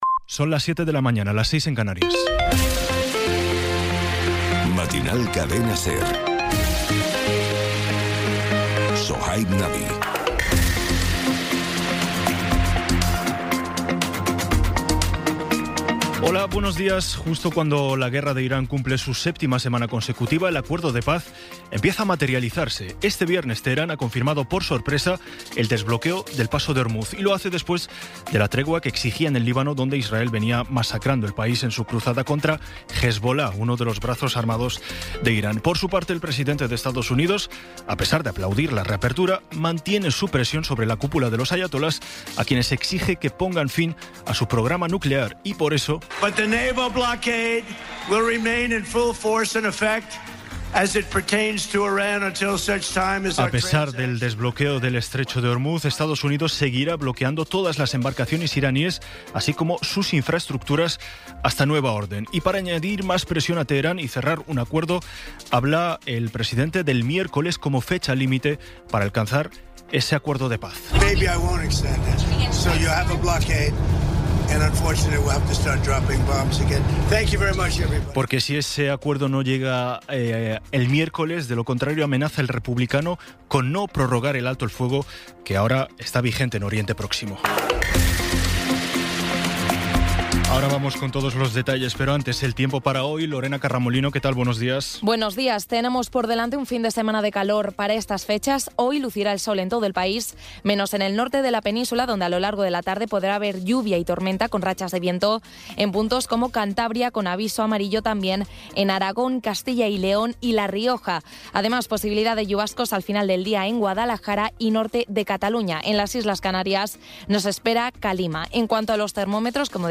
Resumen informativo con las noticias más destacadas del 18 de abril de 2026 a las siete de la mañana.